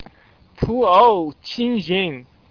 Start (High Speed Internet Only: mouse click the sentence number to hear its pronunciation in standard Chinese)